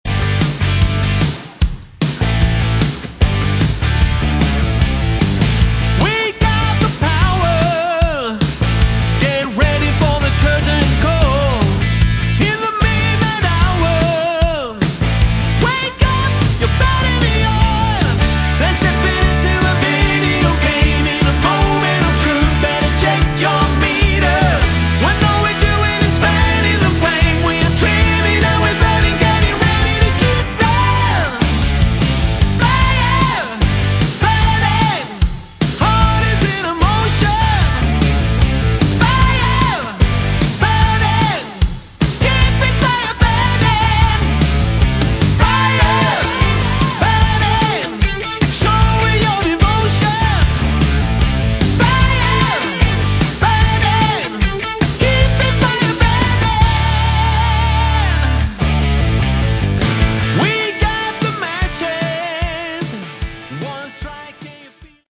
Category: AOR / Melodic Rock
lead vocals, guitars
keyboards
bass
drums